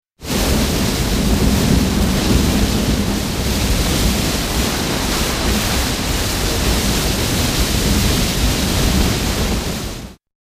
Waterfall(164K) -Cicada(164K)